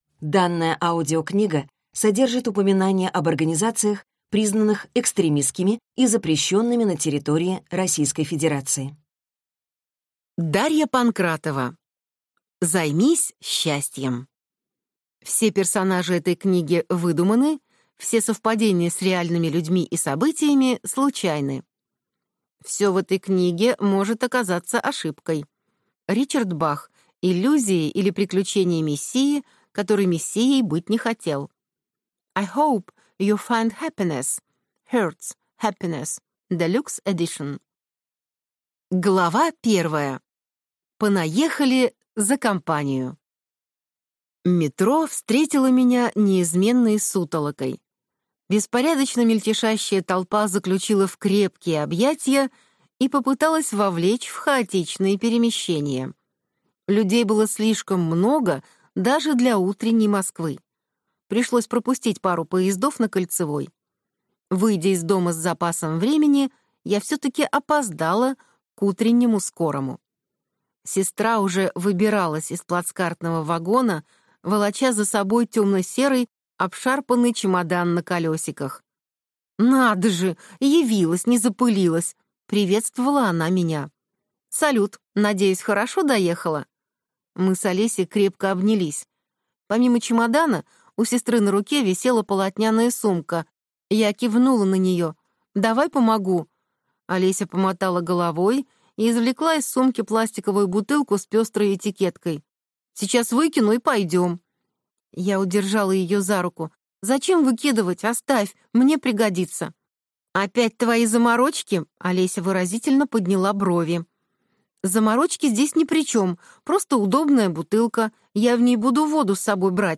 Аудиокнига Займись счастьем | Библиотека аудиокниг